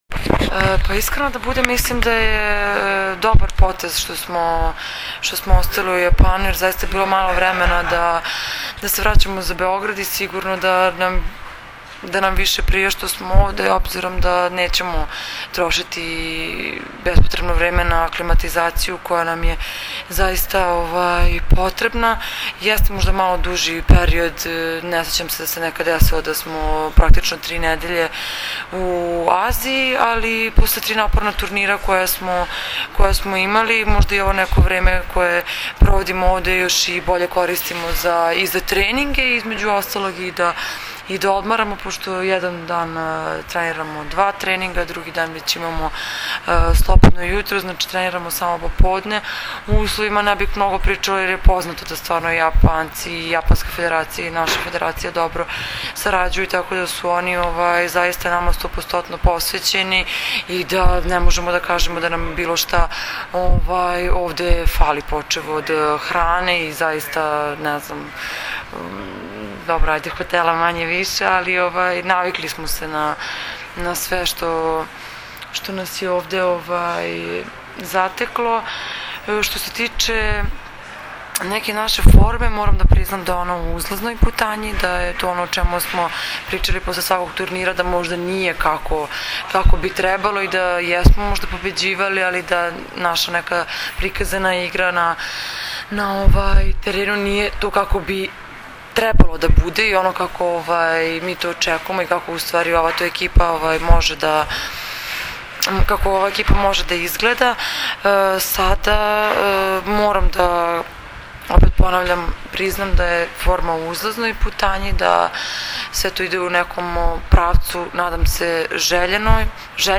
IZJAVA MAJE OGNJENOVIĆ, KAPITENA SRBIJE